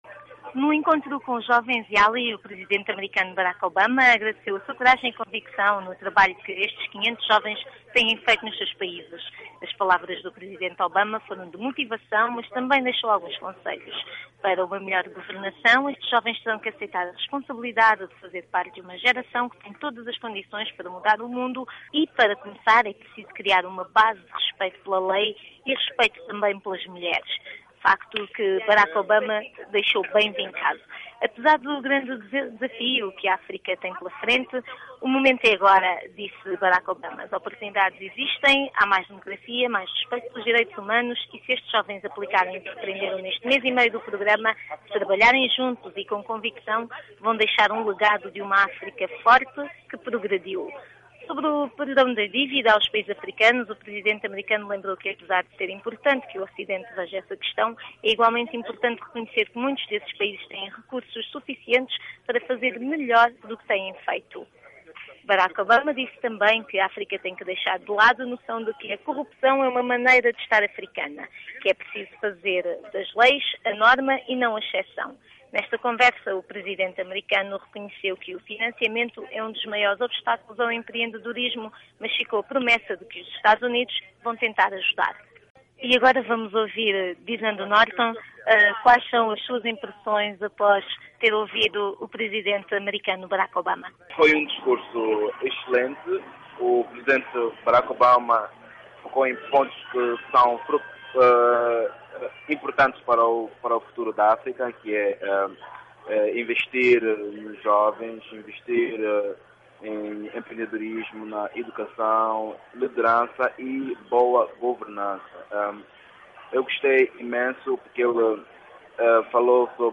Obama fala a 500 jovens líderes africanos - 4:00